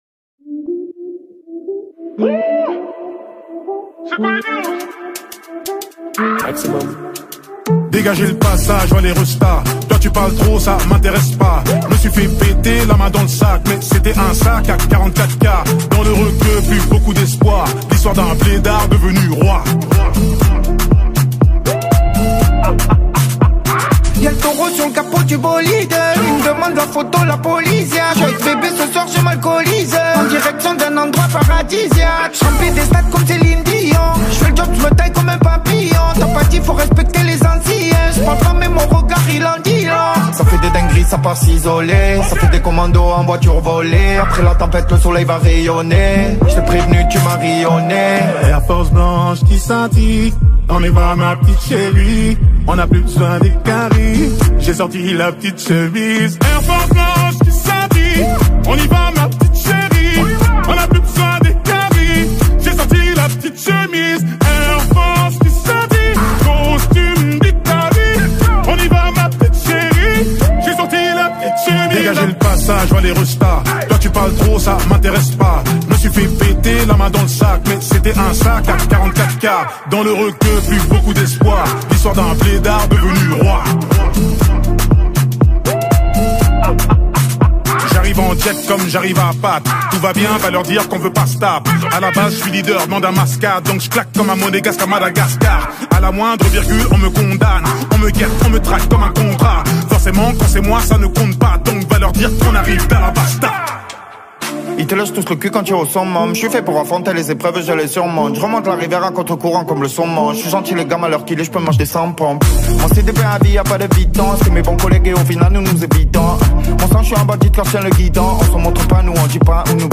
| Pop